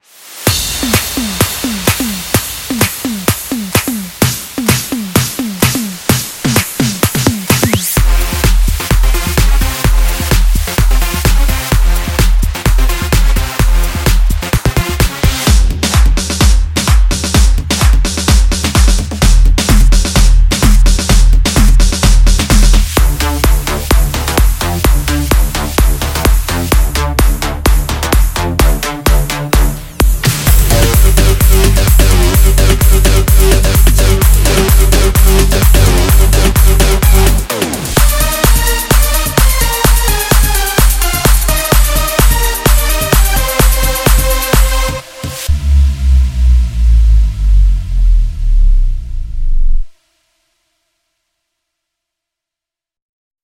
在我的第一个Sample Collection中，您会找到脚踢，帽子，拍手，圈套器，汤姆，FX和合成器镜头。
EDM
24 LOOPS
139 ONE SHOTS